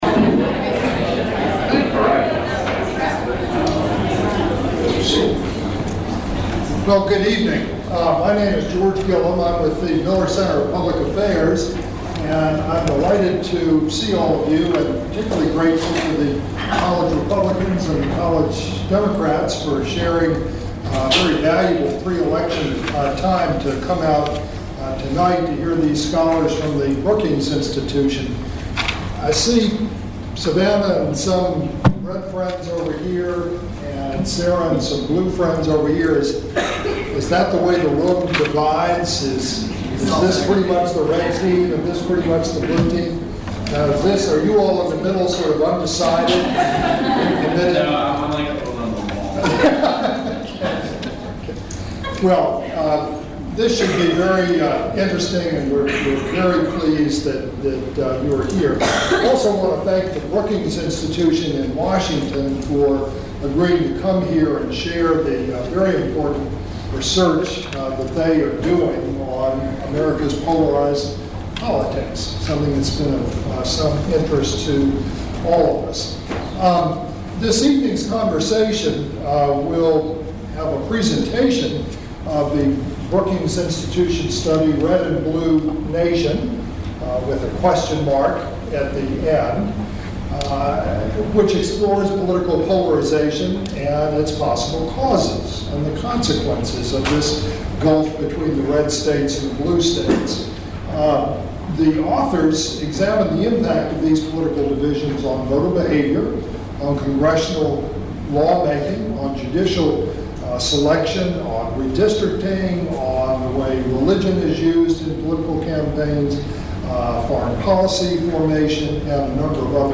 Special Event: 6-7:30 p.m. in Clark 108, UVA Grounds. Bridging partisan divisions has been the touchstone of both the Obama and McCain campaigns.